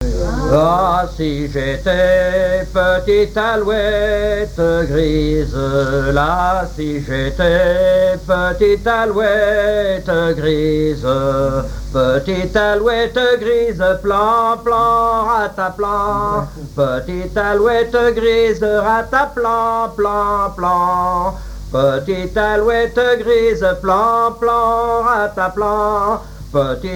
Genre laisse
Pièce musicale inédite